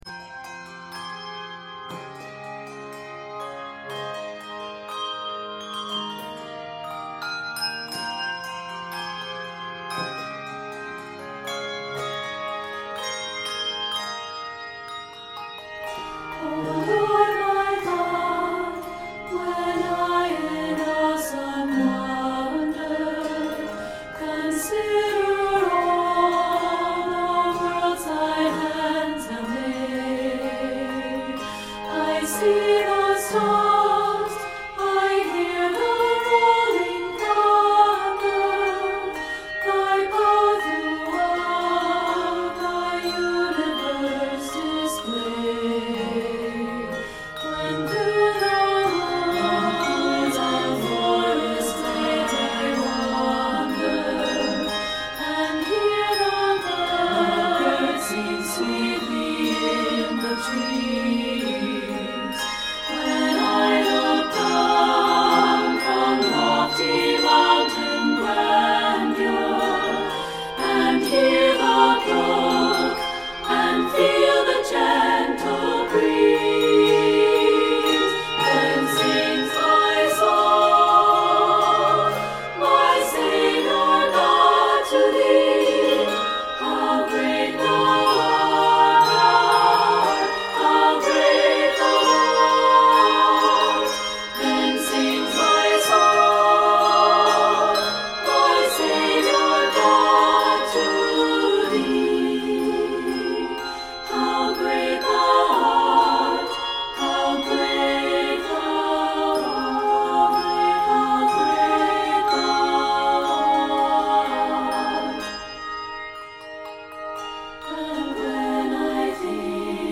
handbell arrangement